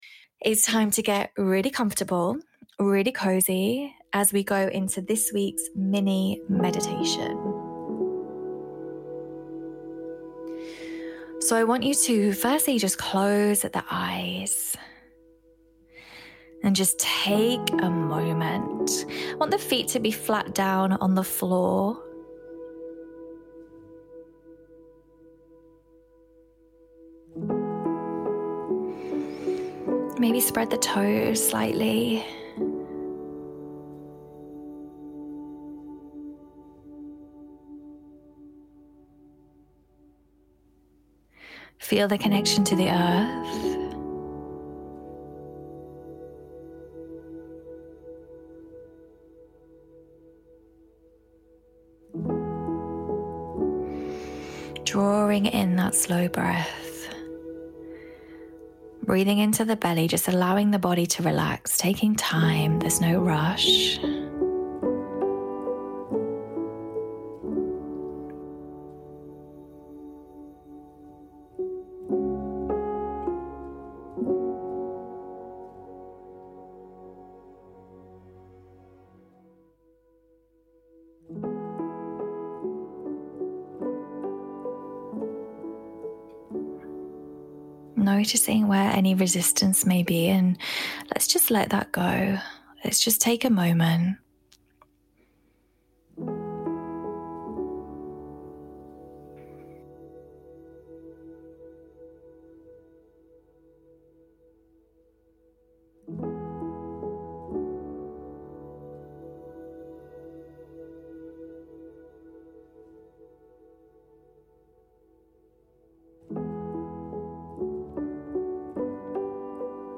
Todays Meditation is all about releasing. We focus so much on what we are drawing in to our lives but what are we actually trying to release? and why can this sometimes feel so difficult?